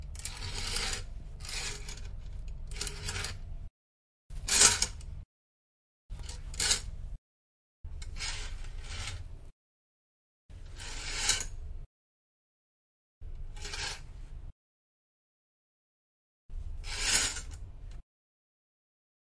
内部运输 " 百叶窗
描述：采取百叶窗 移动百叶窗。
Tag: 滑动 移动 窗口 百叶窗 滚动